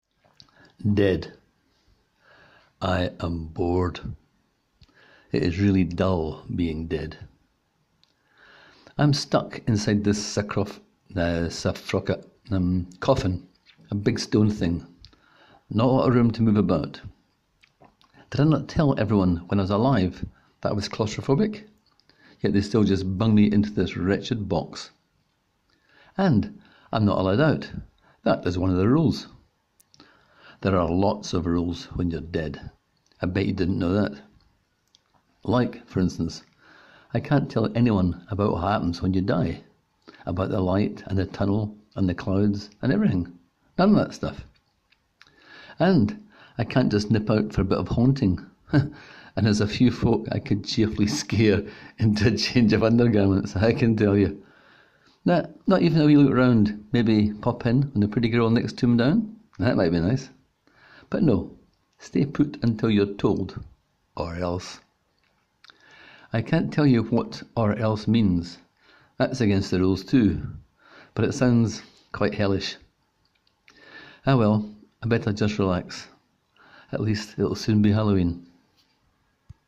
Click here to hear the story read by the author: